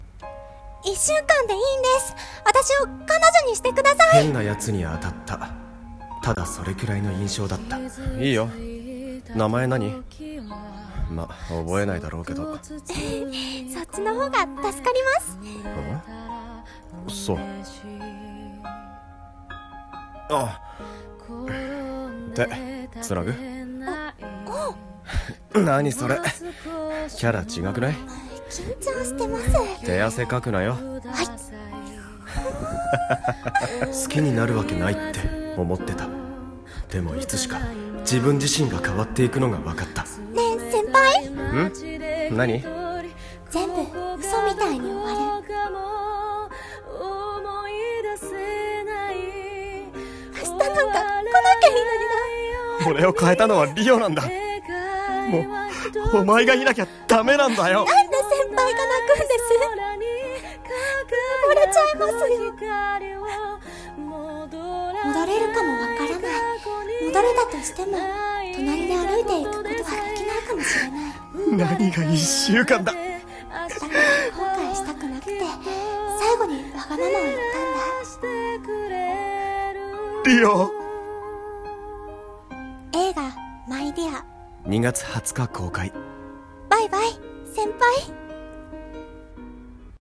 予告風声劇